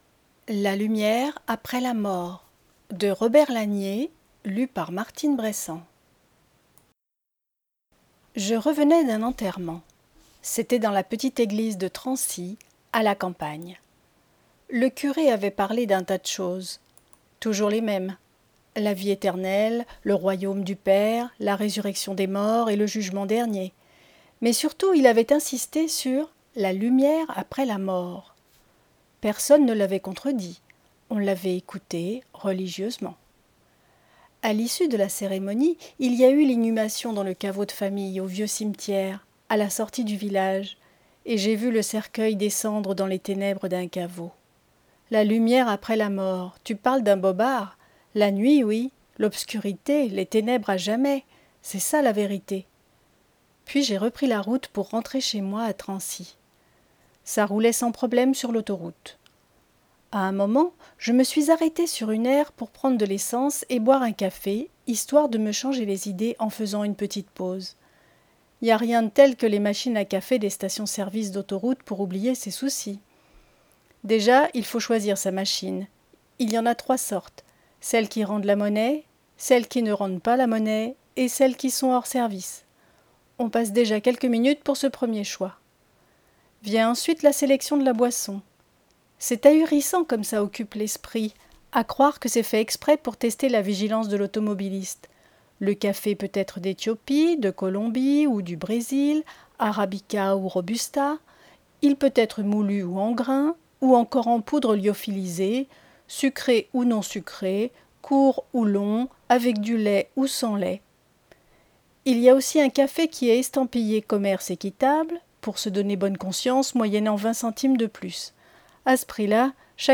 Lecture � haute voix - La lumi�re apr�s la mort